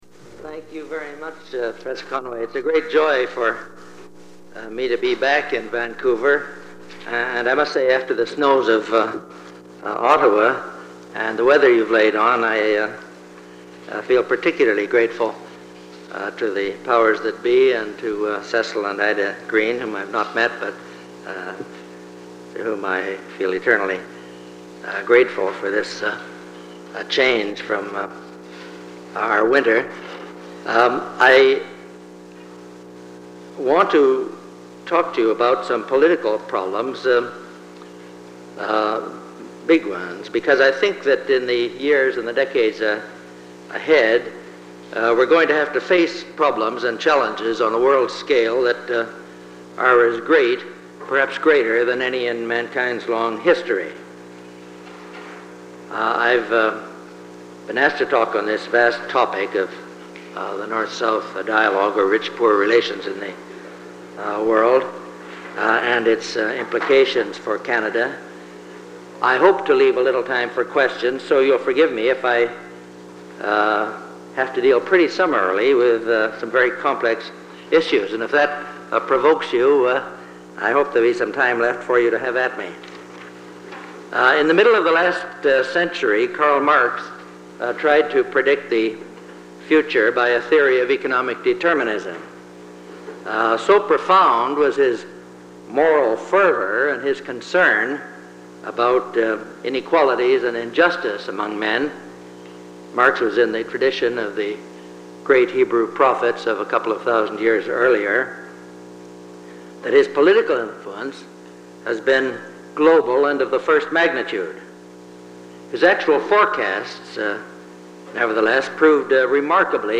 Item consists of a digitized copy of an audio recording of a Cecil and Ida Green Lecture delivered at the Vancouver Institute by Arnold C. Smith March 4, 1978.